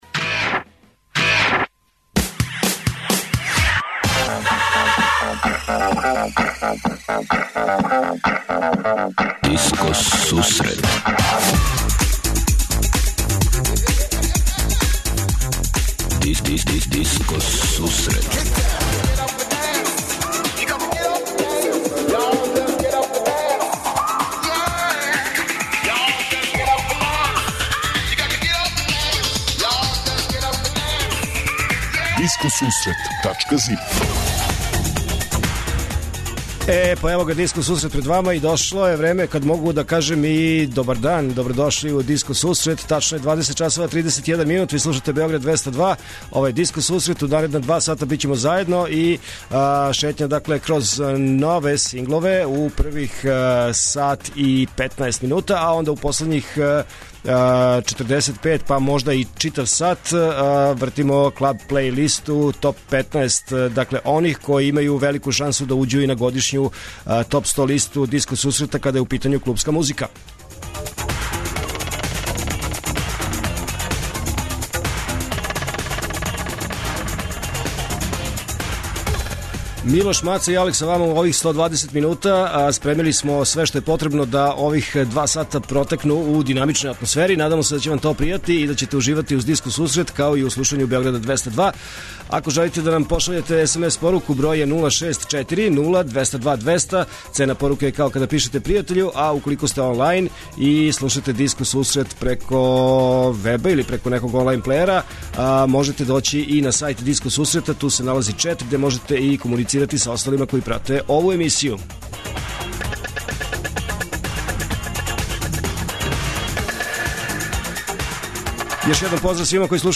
Од 20:30 Диско Сусрет Топ 40 - Топ листа 40 највећих светских диско хитова.